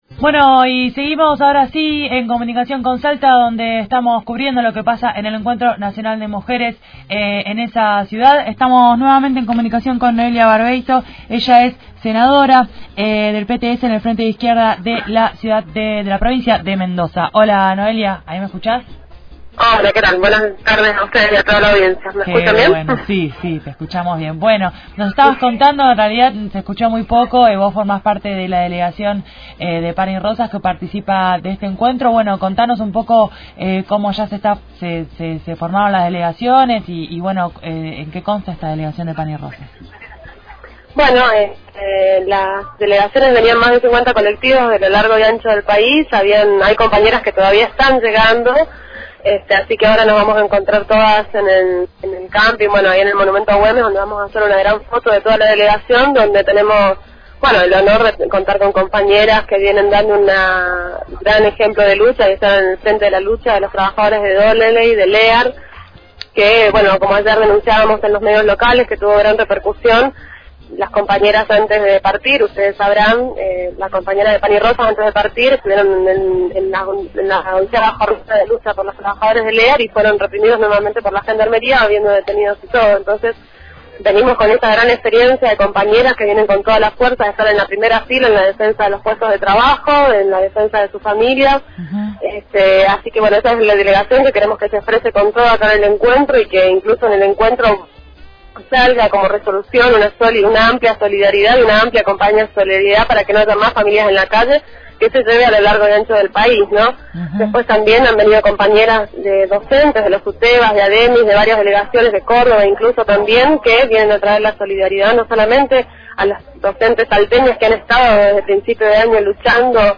Entrevista a Noelia Barbeito, senadora por Mendoza
noelia_barbeito_-_encuantro_naiconal_de_mujeres_-_salta.mp3